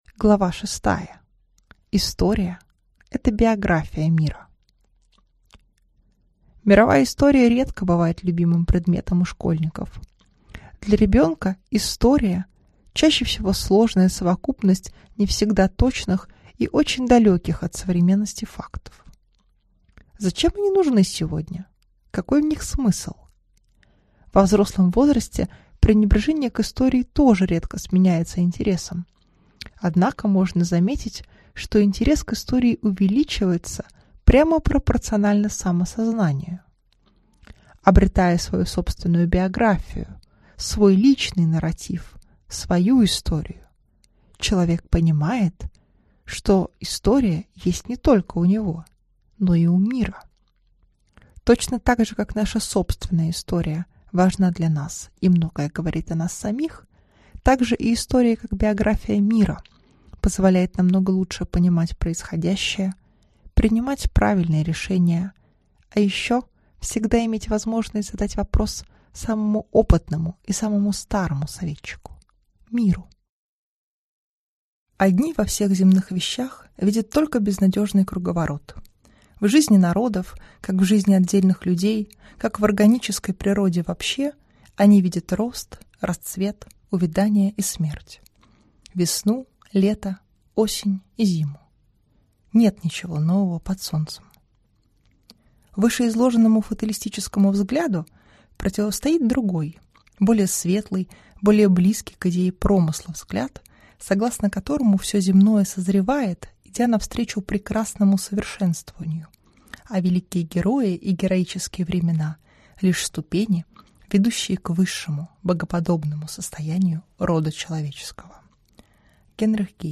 Aудиокнига История